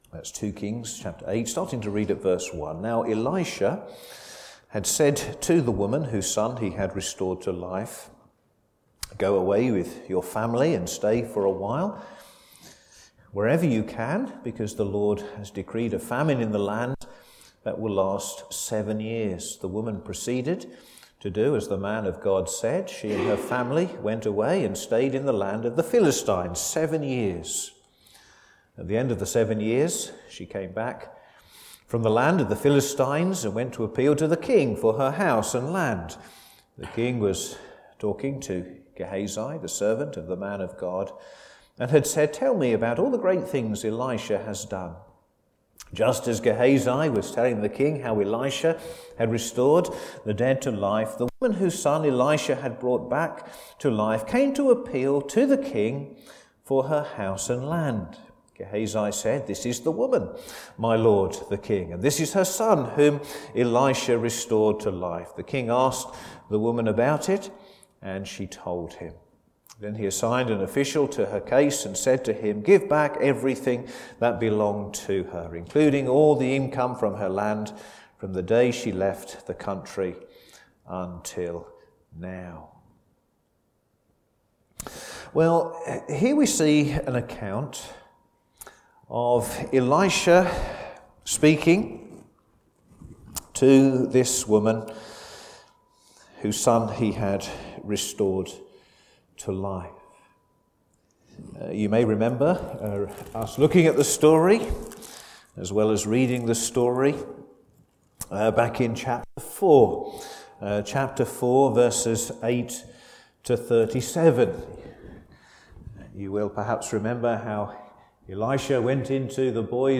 Sermons
Service Evening